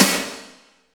55.01 SNR.wav